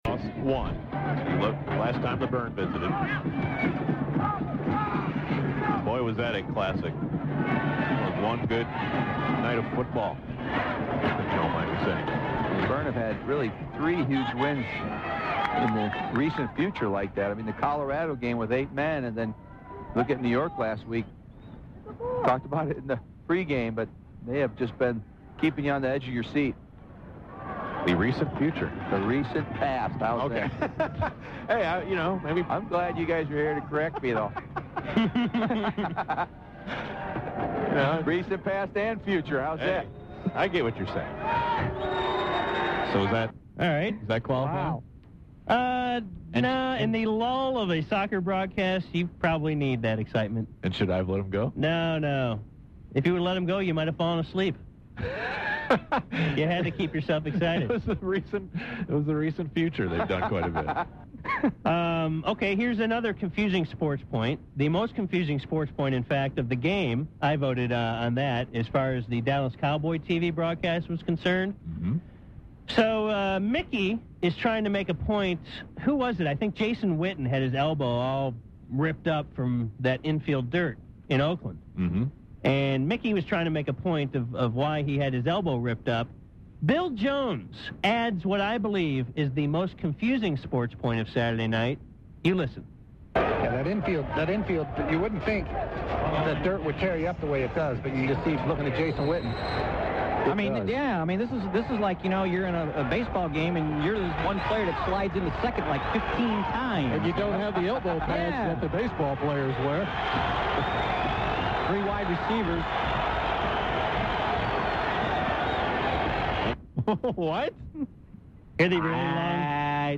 confusing sports points by various broadcasters